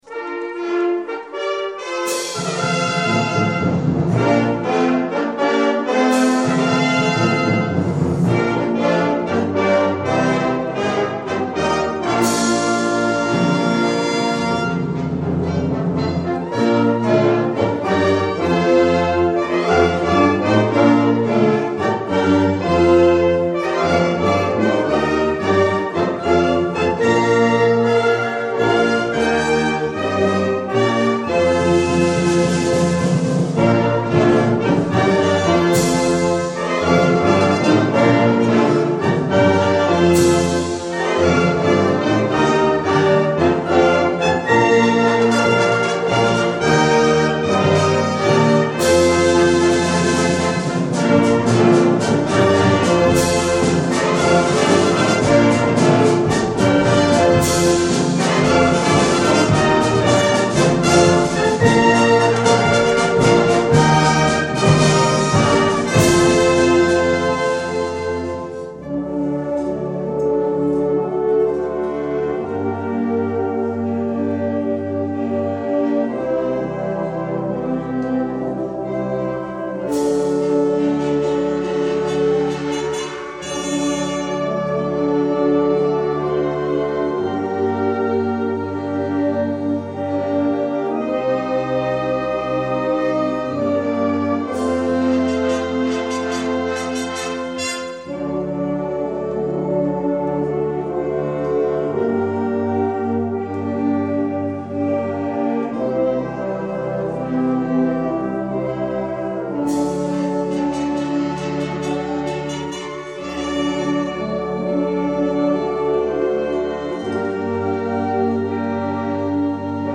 Kategorie Blasorchester/HaFaBra
Unterkategorie Festliche Musik, Fanfare, Hymne
Besetzung Ha (Blasorchester)
Zusatzinfo/Inhalt Ein Neuanfang - Festliche Eingangsmusik